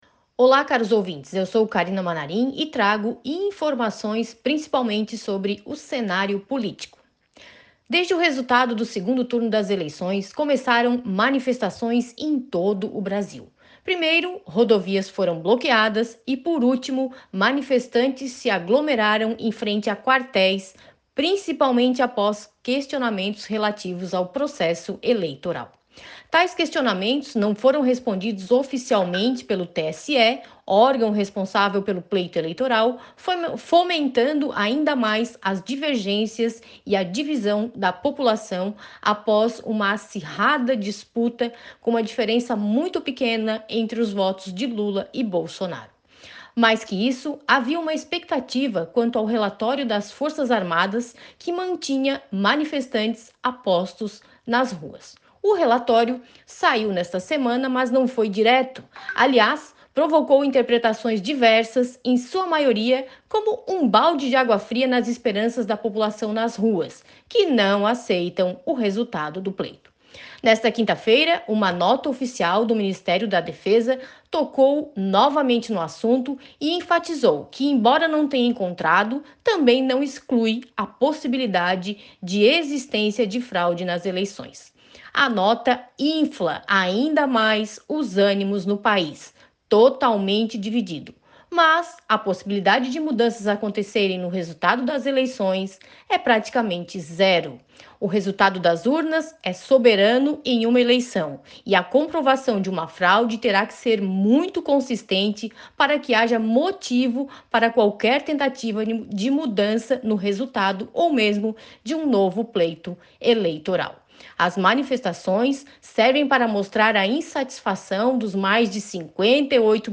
Jornalista destaca o cenário político após o resultado do 2º turno das eleições, que gerou manifestações e questionamentos pelo país